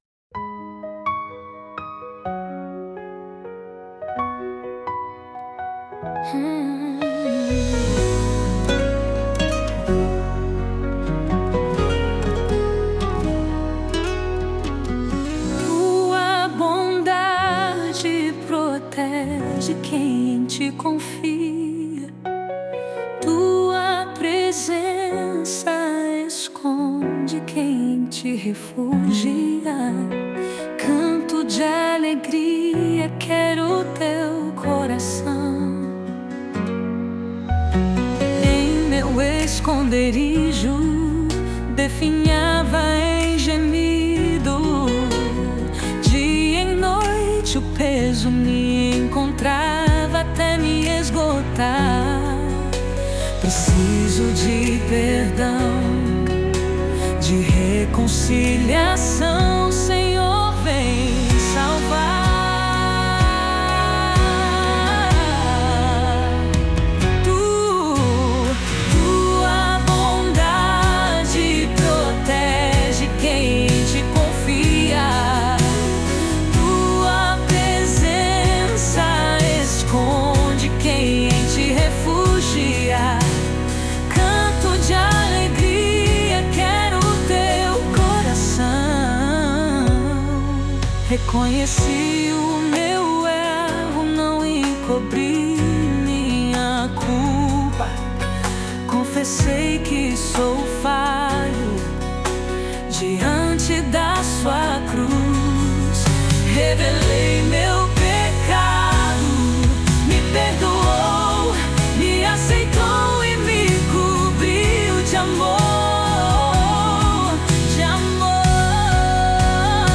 SLM 32 voz feminina.mp3